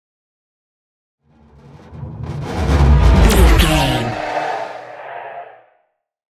Sci fi super speed vehicle whoosh
Sound Effects
futuristic
intense
whoosh